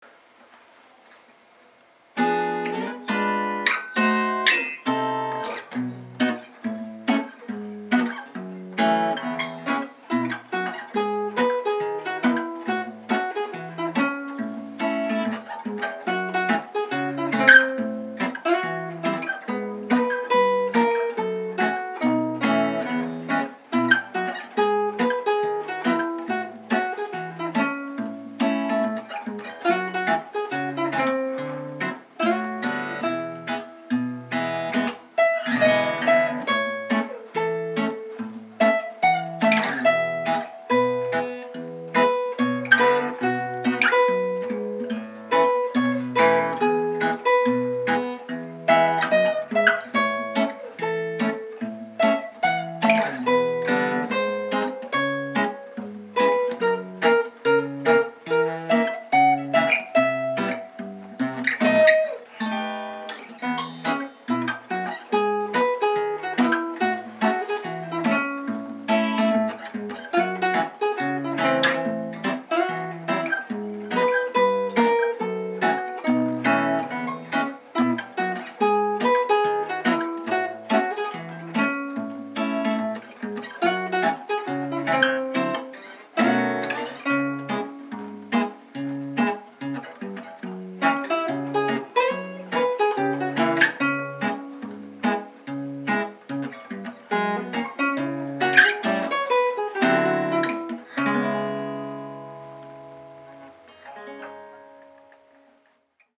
そんなワクワク感を曲にしてみました。
ただ、食べちゃうとなくなるのでエンディングは悲しく終わります・・・
リードギター